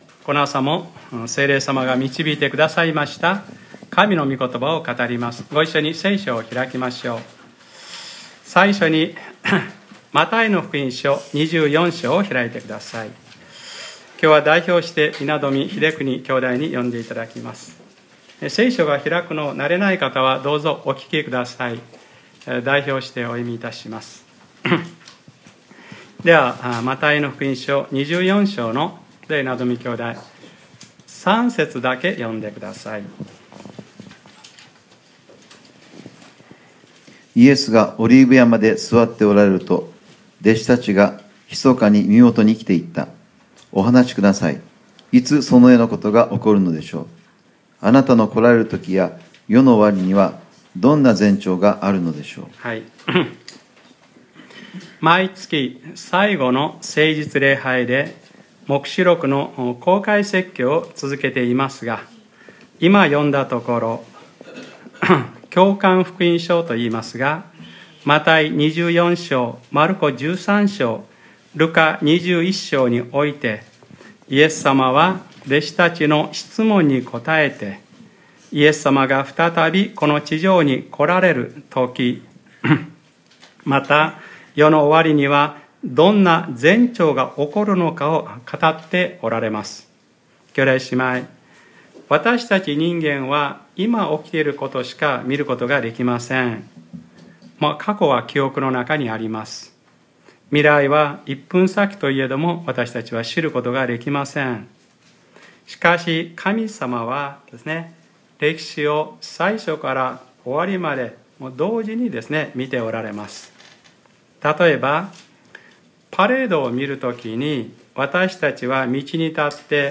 2014年6月29日（日）礼拝説教 『黙示録ｰ３１：患難前に起こること』